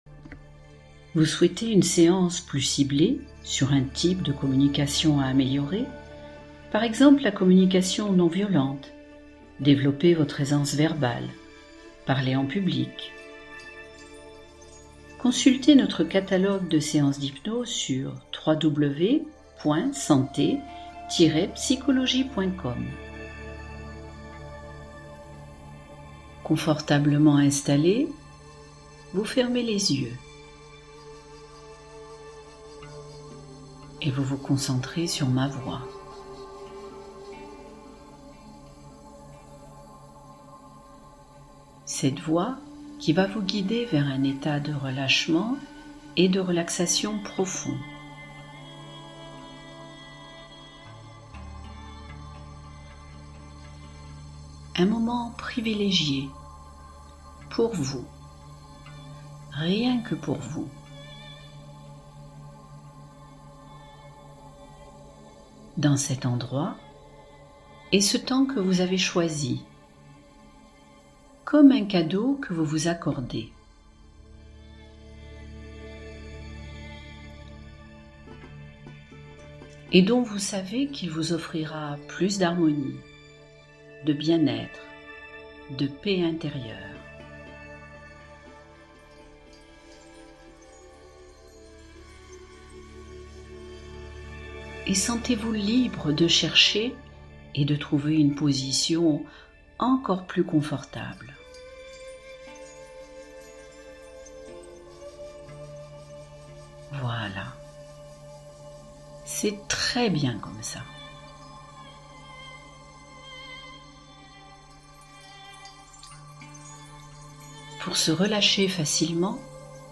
Transformez vos relations en 45 minutes grâce à cette hypnose de communication magnétique